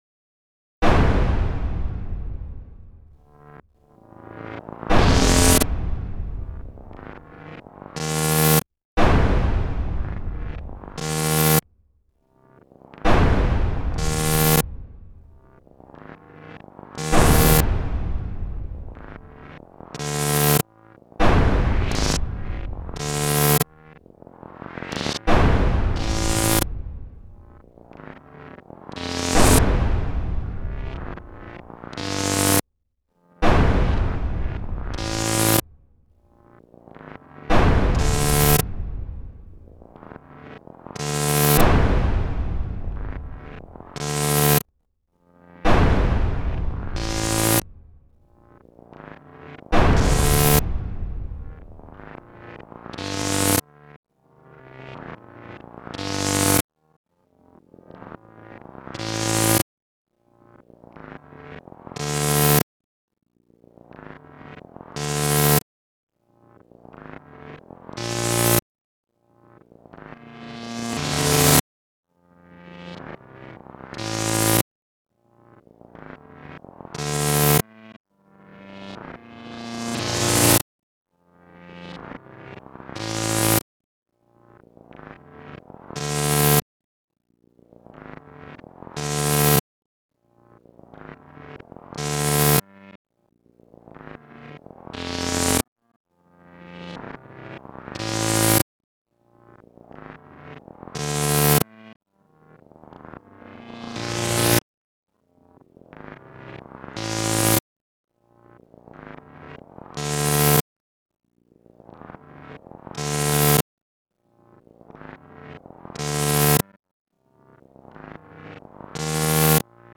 AI generated auditory artwork